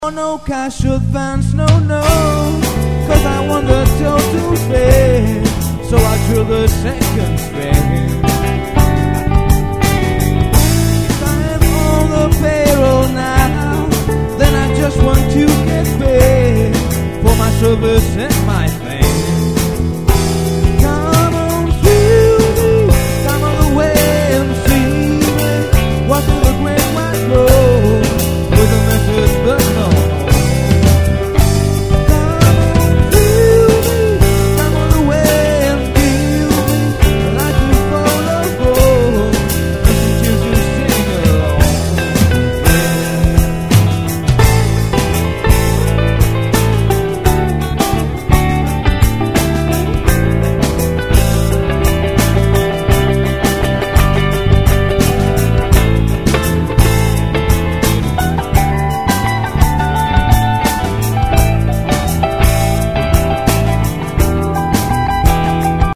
The 60s and 70s R & B re-visited